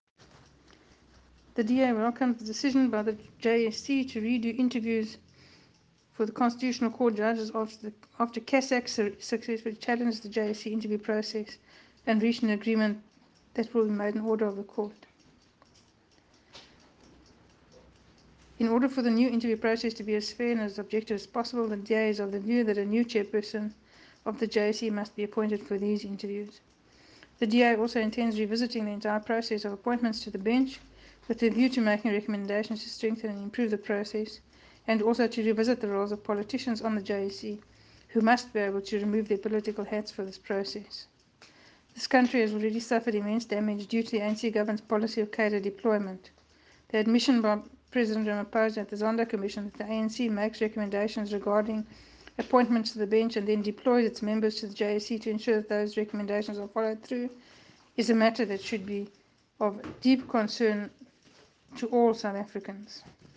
soundbite by Adv Glynnis Breytenbach MP.